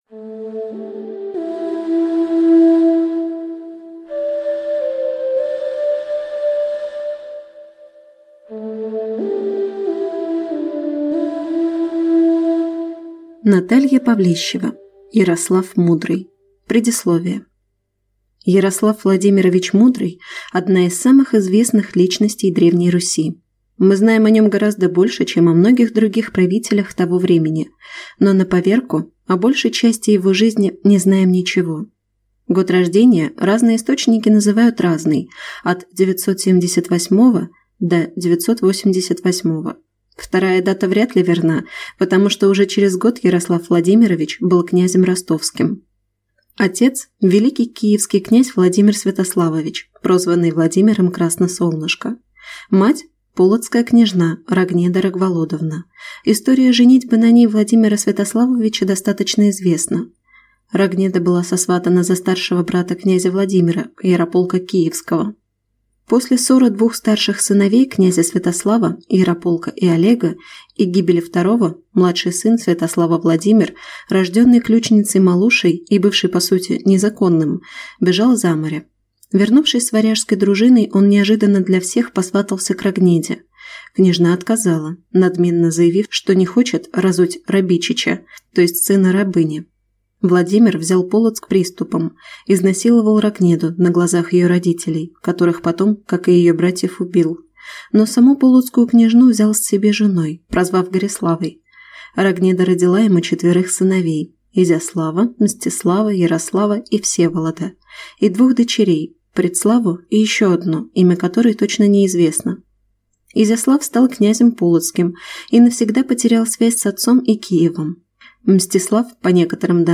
Аудиокнига Ярослав Мудрый | Библиотека аудиокниг
Прослушать и бесплатно скачать фрагмент аудиокниги